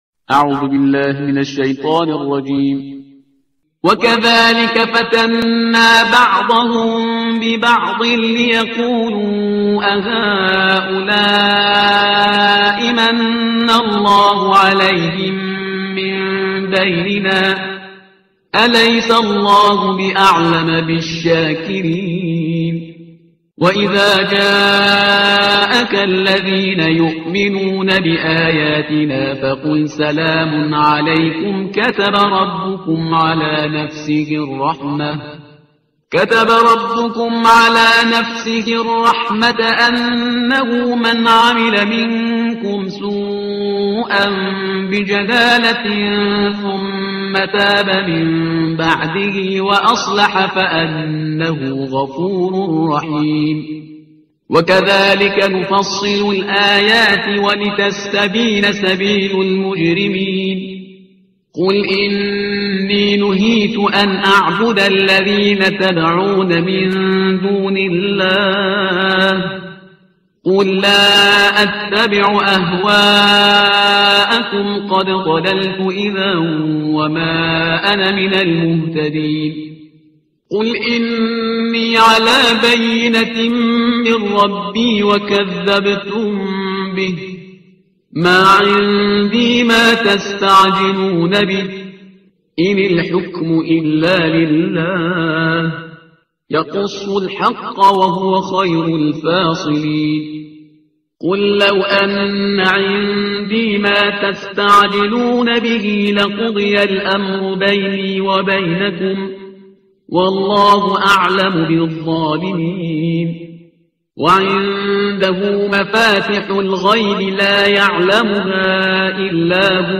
ترتیل صفحه 134 قرآن